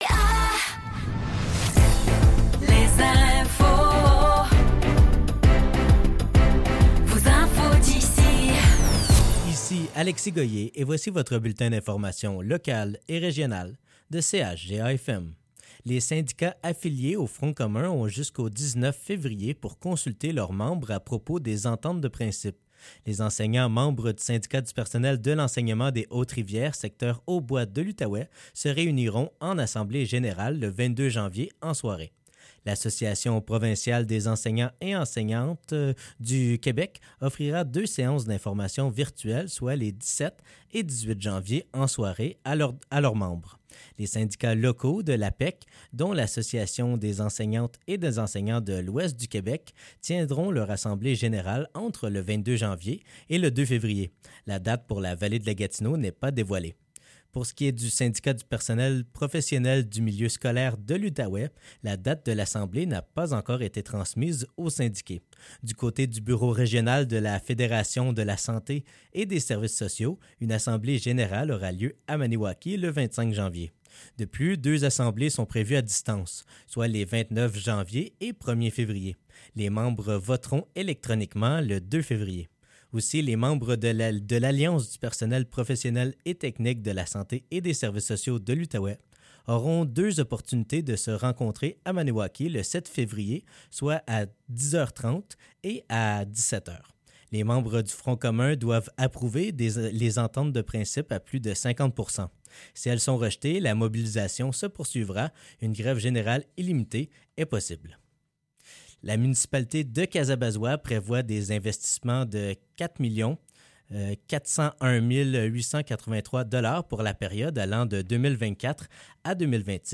Nouvelles locales - 16 janvier 2024 - 15 h